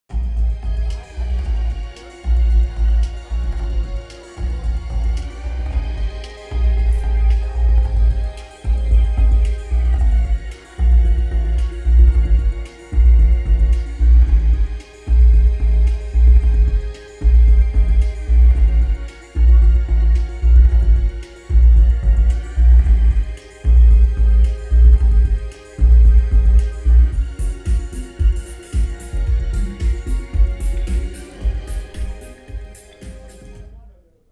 جشنواره موزیک زیرزمینیه هلند
به سبک اپرائی